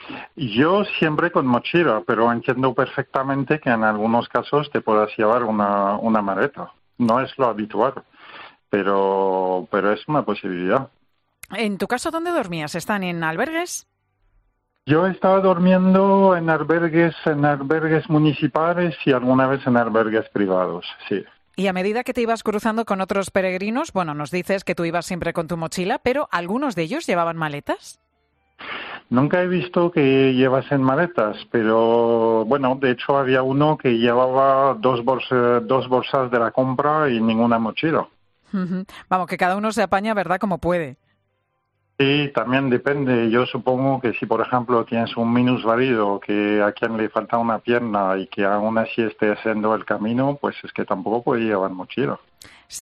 No te pierdas el testimonio de este peregrino hablando sobre una medida que afecta a su viaje